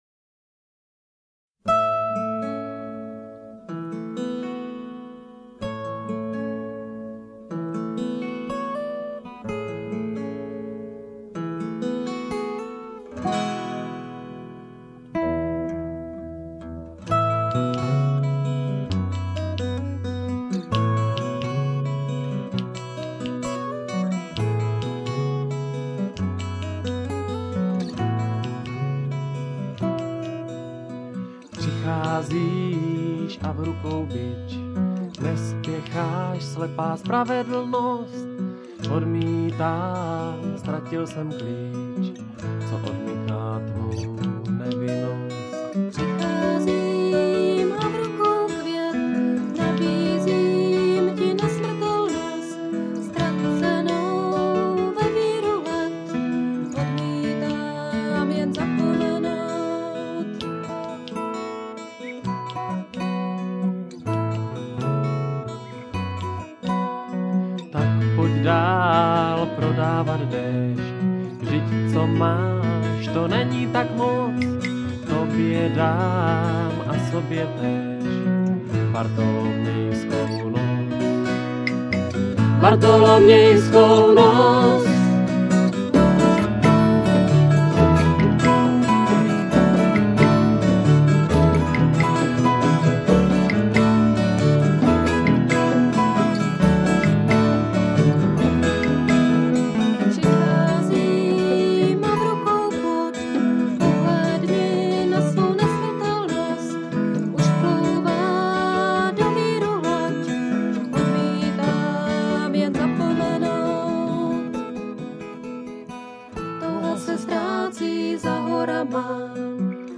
DEMO-DOMA 1996, MP3